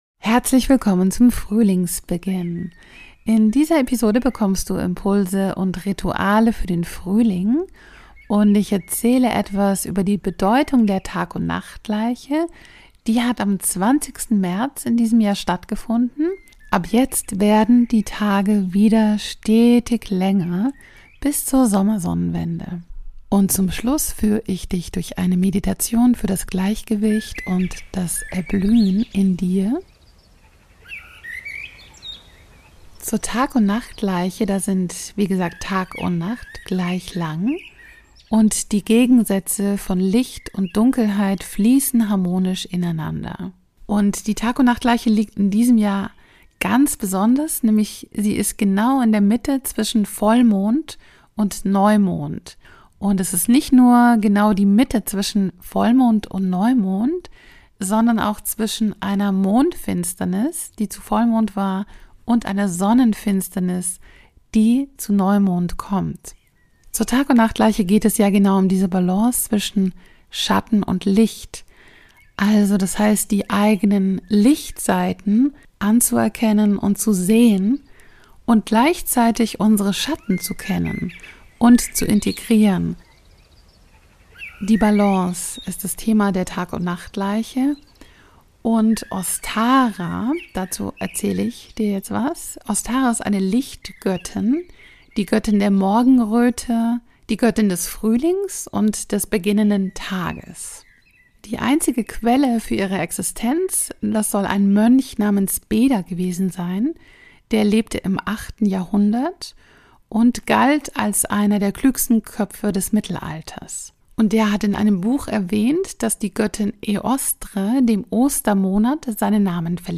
Frühling, Tagundnachtgleiche, Ostara und eine Meditation für das Gleichgewicht und das Erblühen